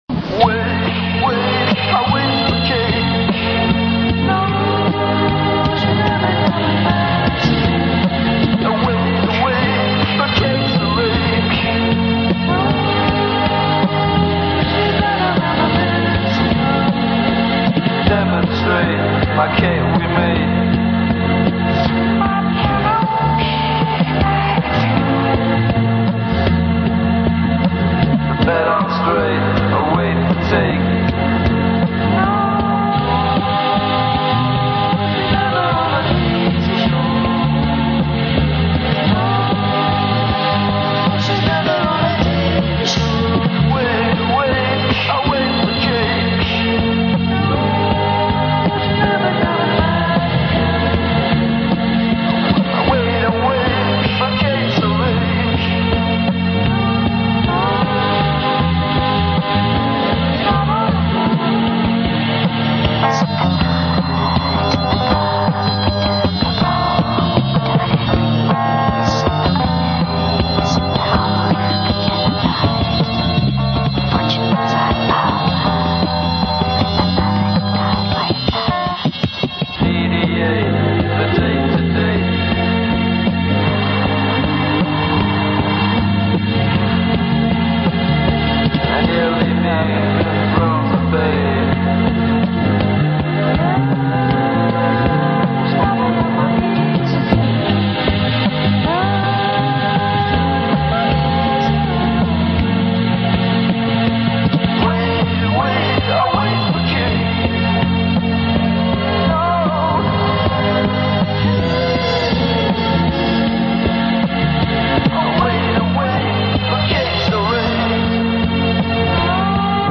Lo-fi goodness from a bedroom-pop oddball.